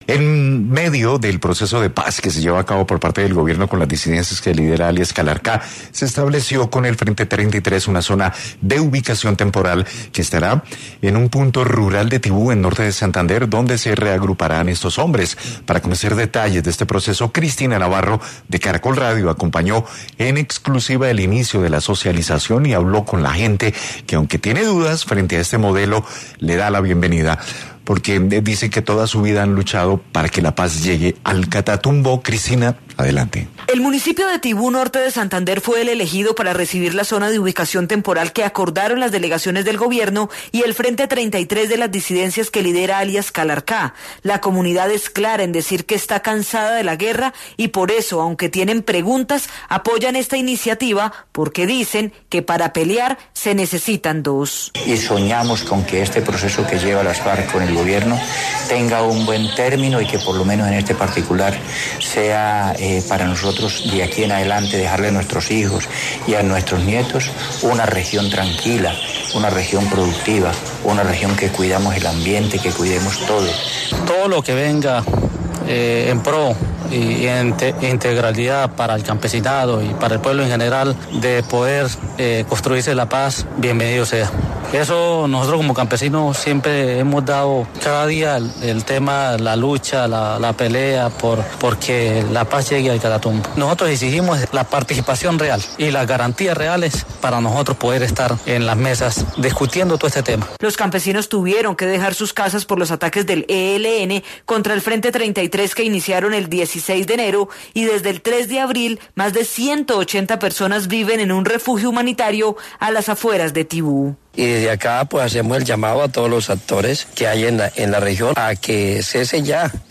Testimonios de campesinos desde Tibú: “siempre hemos luchado para que la paz llegue al Catatumbo”